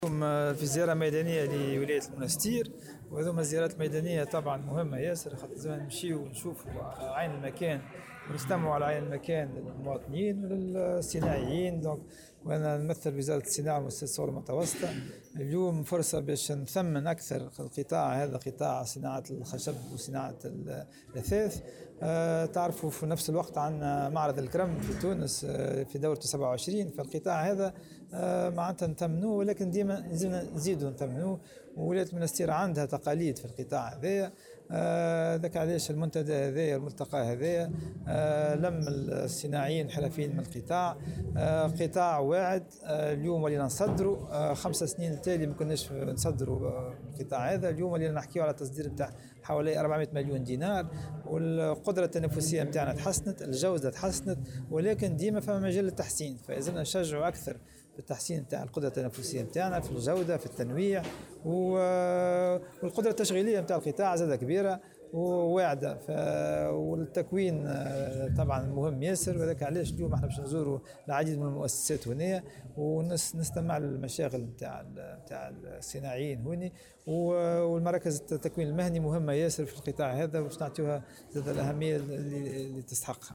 وأضاف في تصريح لمراسل "الجوهرة أف أم" على هامش ملتقى جهوي حول إستراتيجية النهوض بقطاع الخشب والتأثيث بولاية المنستير، أنه يجب تطوير هذا القطاع وتحسين التكوين، والعمل من أجل مزيد تنمية صادراته التي بلغت حوالي 400 مليون دينار.